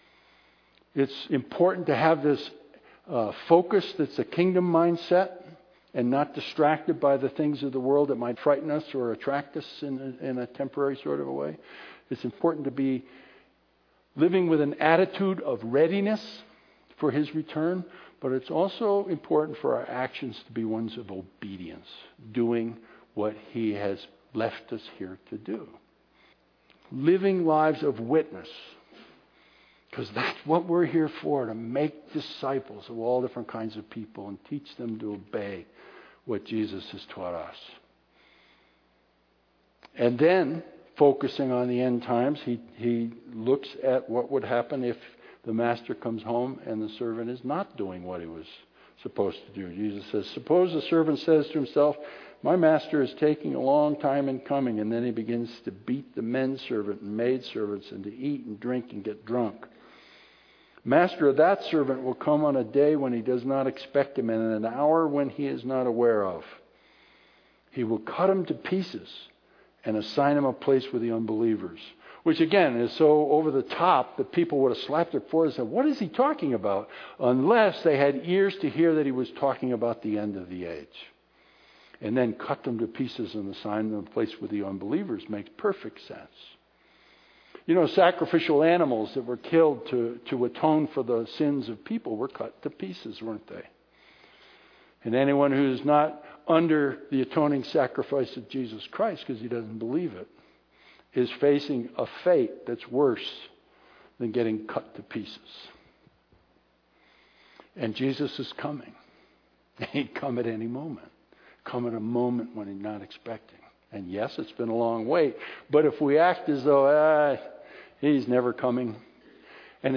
This is the concluding section of a message on being ready for Jesus’ return.